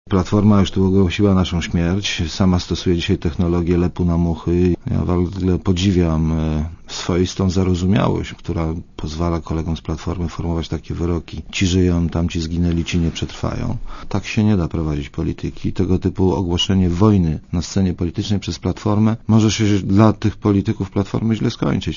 _**całą rozmowę Moniki Olejnik z Wiesławem Kaczmarkiem**_